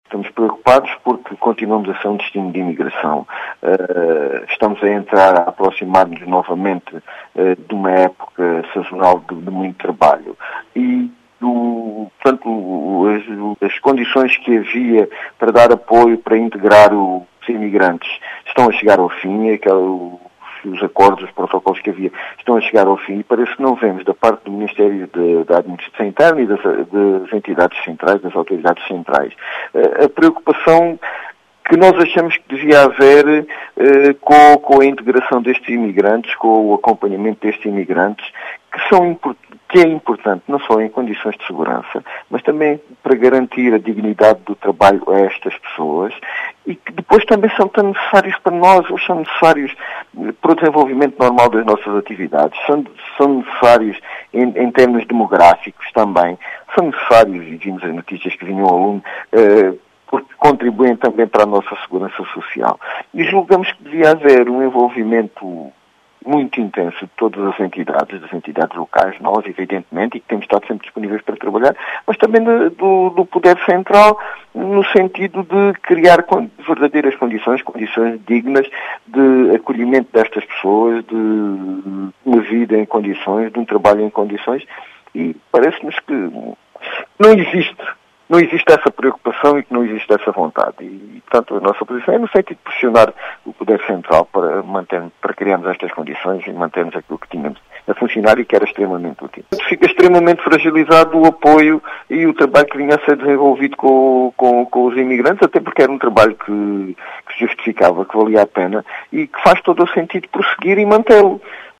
As preocupações foram deixadas por João Efigénio Palma, presidente da Câmara Municipal de Serpa, que diz fazer todo o sentido em manter este trabalho que vinha a ser desenvolvido.